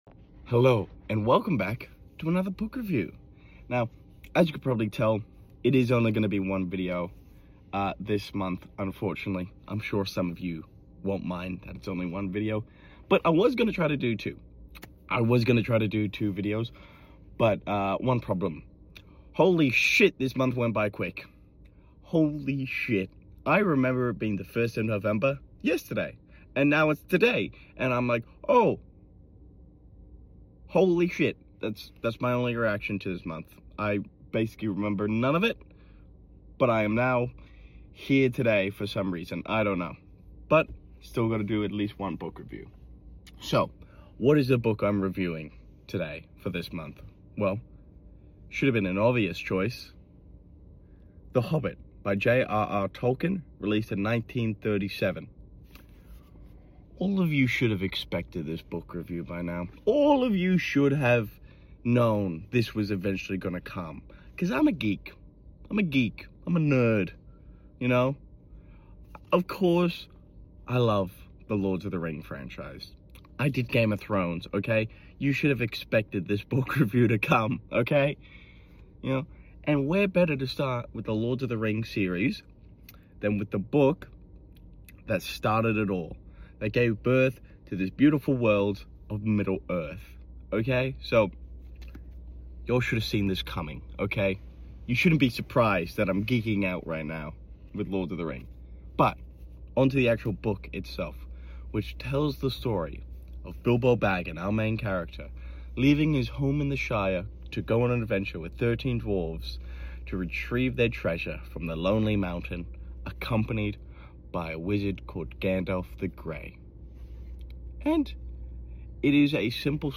Car Book Review: The Hobbit sound effects free download